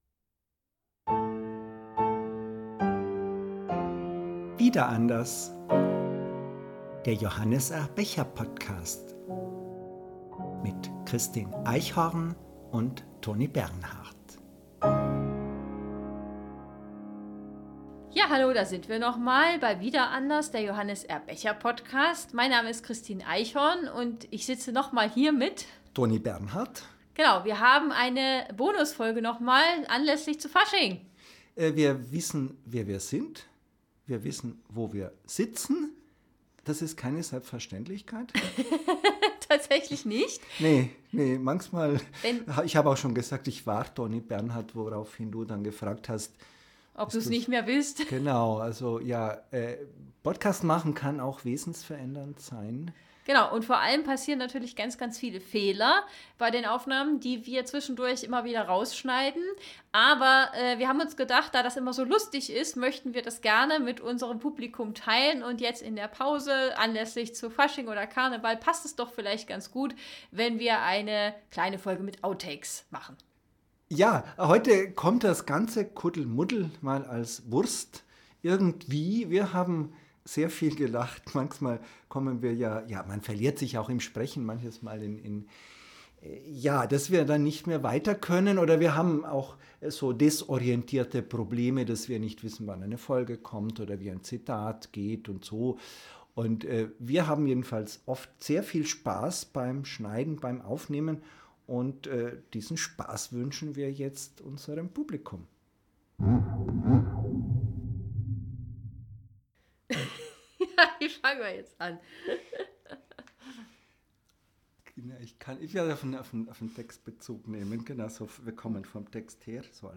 Beschreibung vor 1 Monat Nach 20 Folgen Becher-Podcast "Wiederanders" sind uns beim Aufnehmen immer wieder lustige Dinge passiert.
Musikalisch gesellt sich diesmal den Seilen ein Theremin bei.